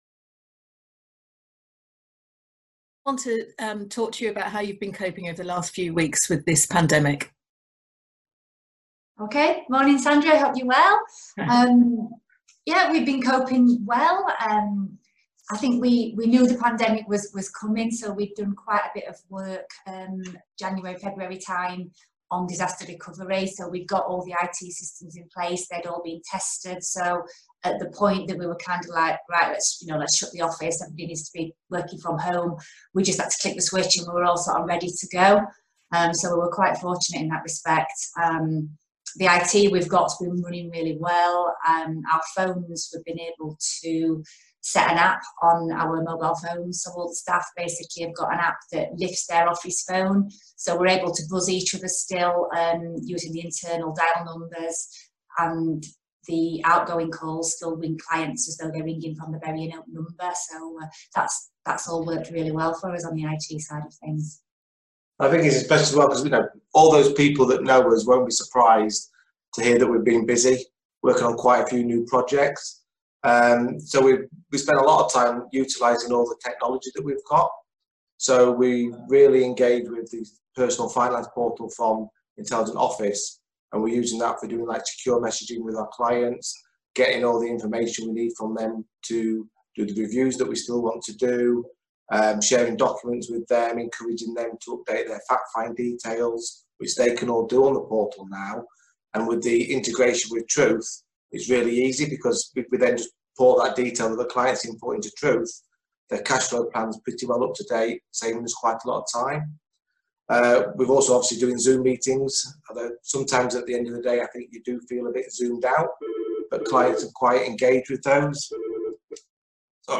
In the fourth interview in the series